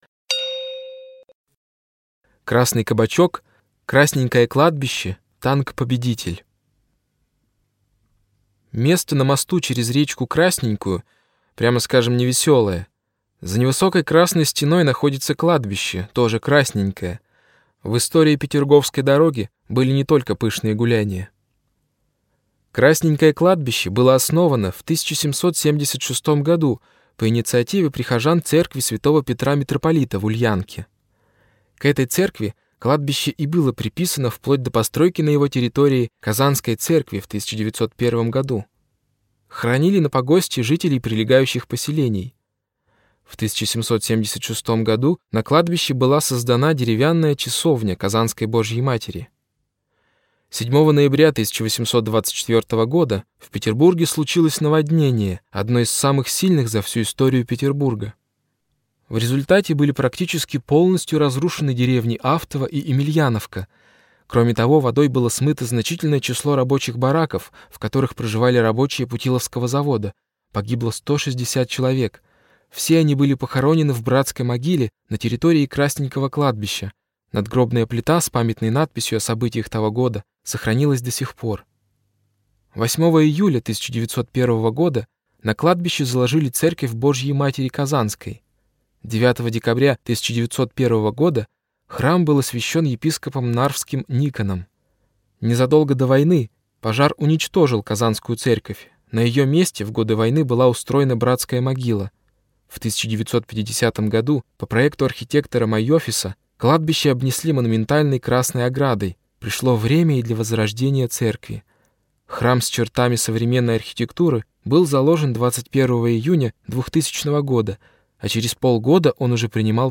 Аудиокнига Петергофская дорога – 2 | Библиотека аудиокниг